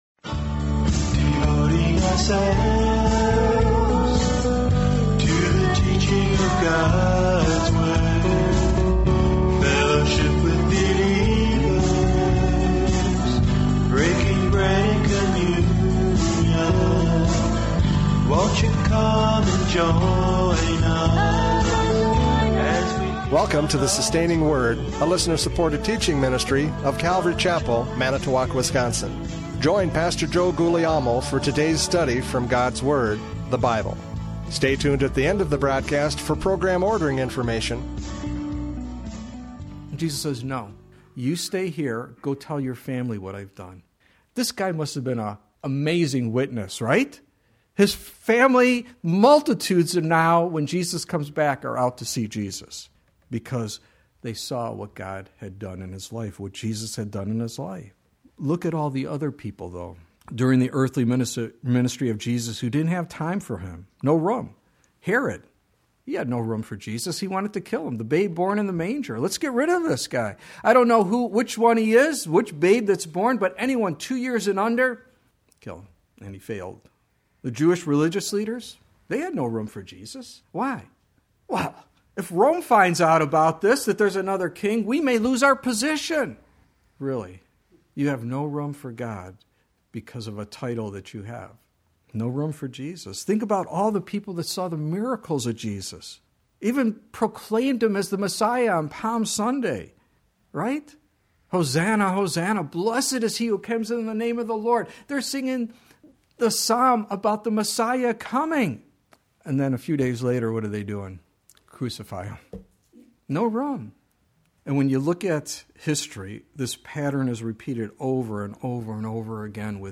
Luke 2:1-7 Service Type: Radio Programs « Christmas 2023 Luke 2:1-7 No Room!